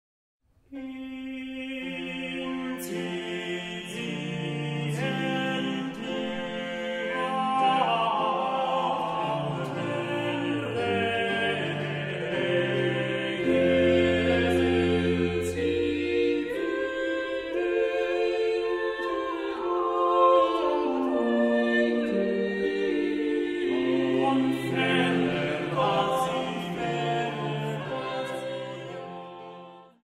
Leitung und Orgel